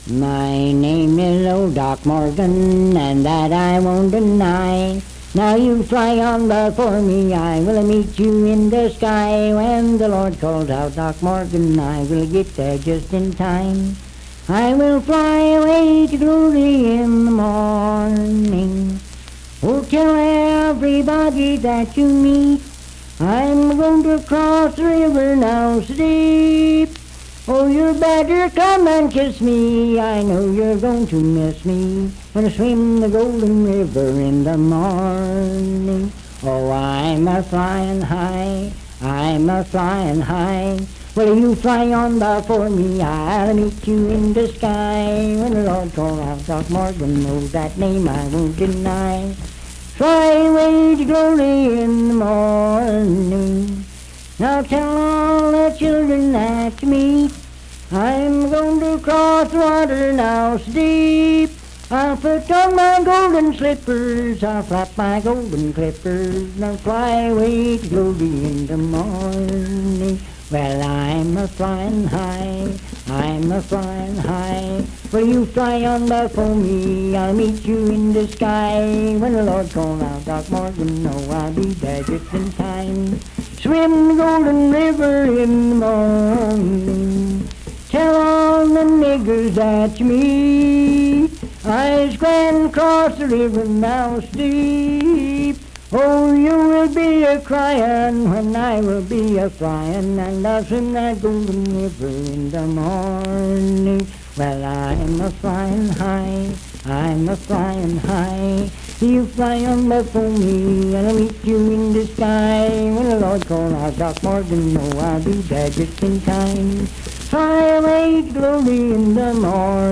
Unaccompanied vocal music
Verse-refrain 3d(7w/R).
Performed in Sandyville, Jackson County, WV.
Hymns and Spiritual Music
Voice (sung)